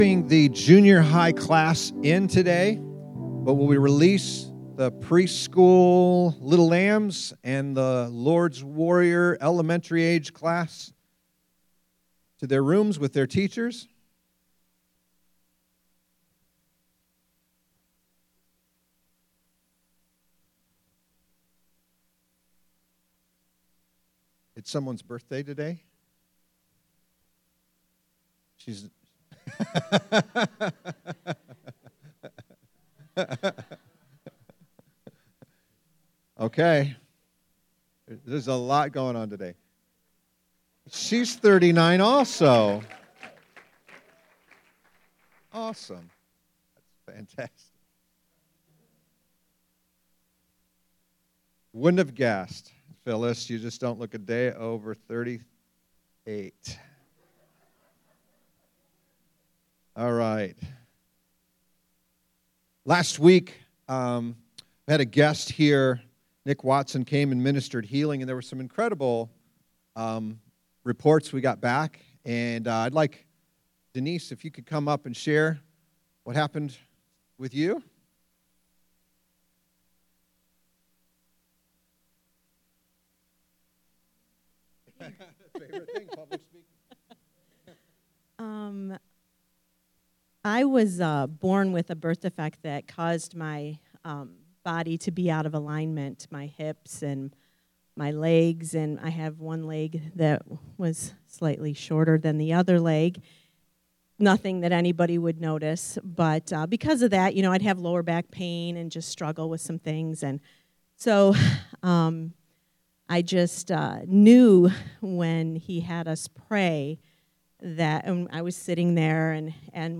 A message from the series "Love Hate."